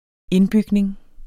Udtale [ ˈenˌbygneŋ ]